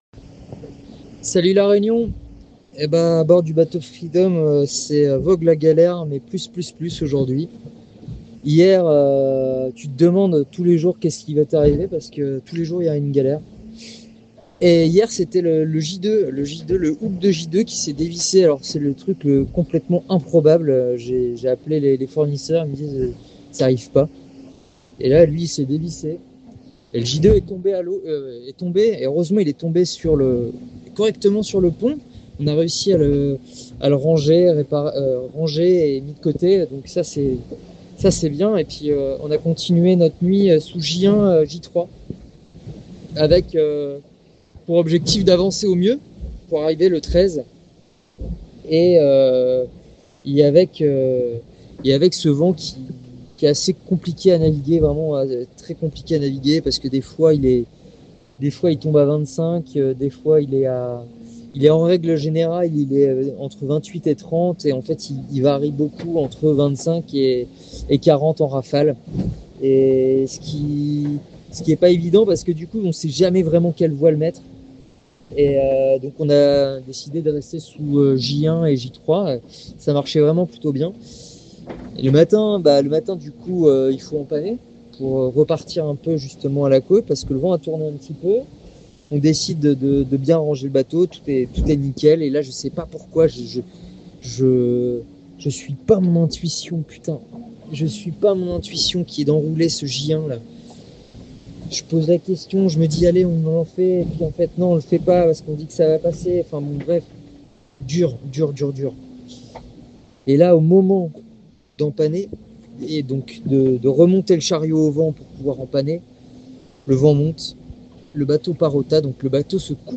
Globe 40 : Le bateau Class40 Free Dom est arrivé à Valparaíso au Chili ! La joie de l’équipage en vidéo !
L’ambiance était électrique sur les quais.